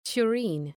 Προφορά
{tʋ’ri:n}
tureen.mp3